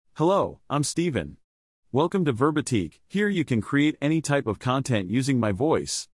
StephenMale US English AI voice
Stephen is a male AI voice for US English.
Voice sample
Listen to Stephen's male US English voice.
Male
Stephen delivers clear pronunciation with authentic US English intonation, making your content sound professionally produced.